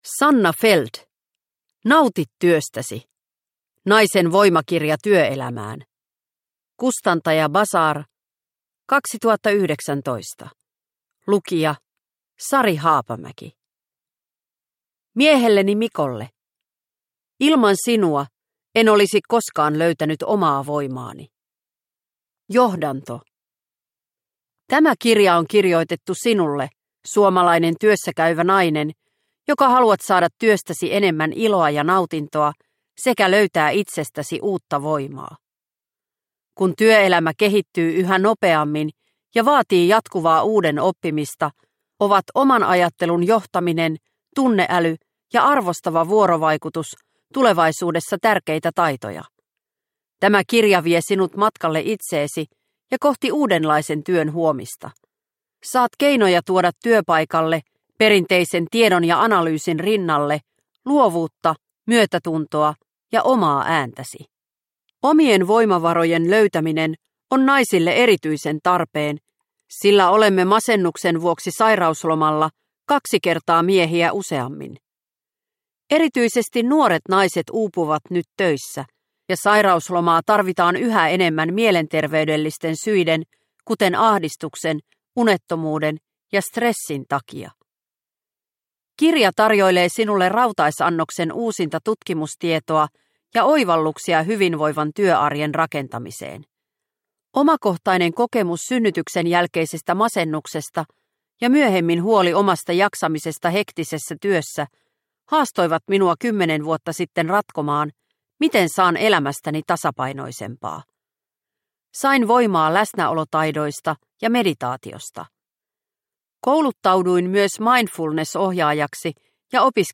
Nauti työstäsi! – Ljudbok – Laddas ner